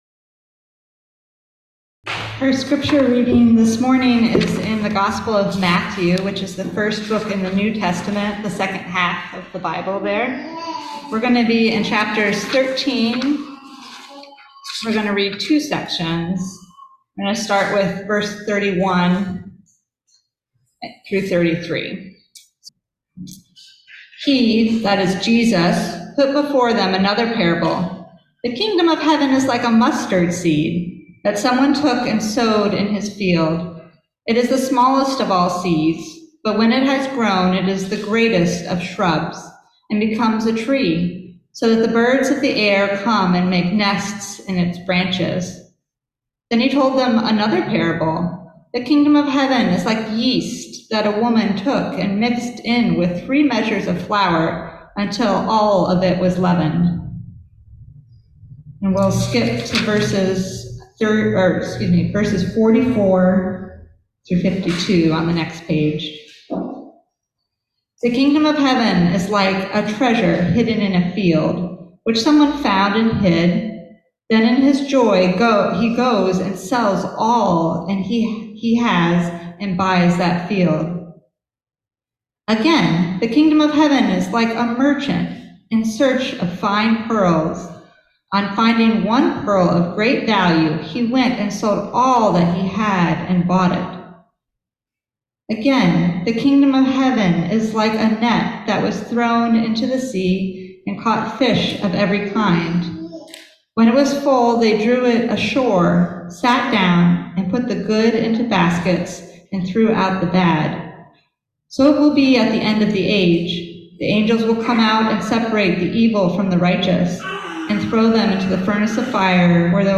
Listen to the most recent message from Sunday worship at Berkeley Friends Church, “The Kingdom of God?”